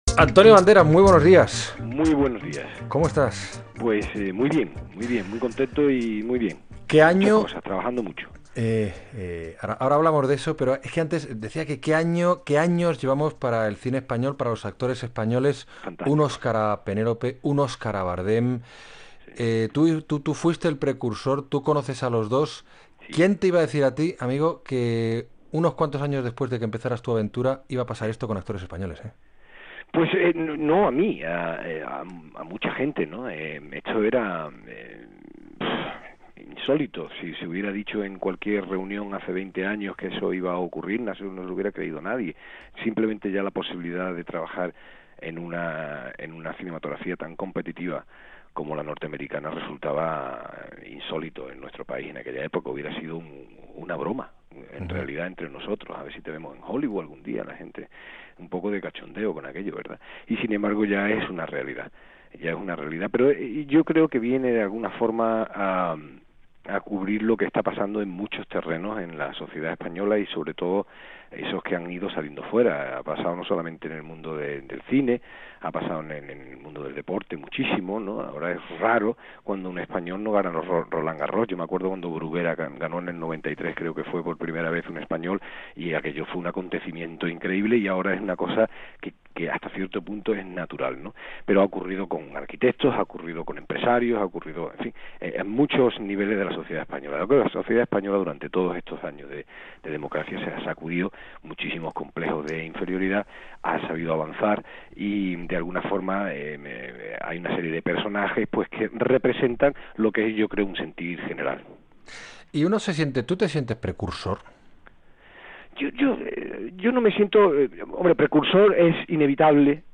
S21 Si tiene acceso a Internet escuche la siguiente entrevista radiofónica. El actor malagueño Antonio Banderas conversa con Juan Ramón Lucas, a quien reconoce sentirse precursor del éxito de los actores españoles en Hollywood.